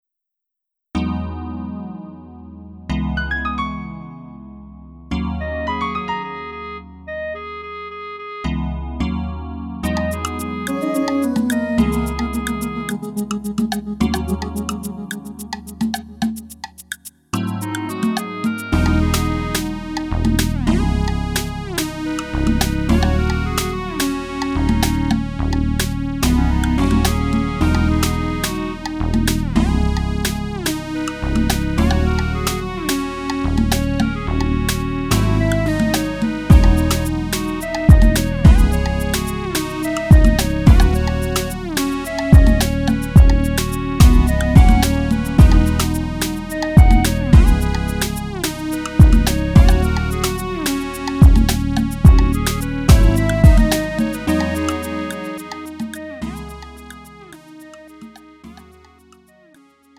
음정 -1키 3:19
장르 가요 구분